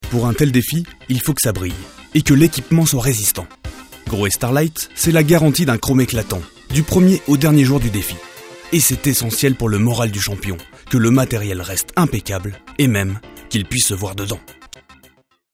Publicité Grohé
30 - 48 ans - Ténor Contre-ténor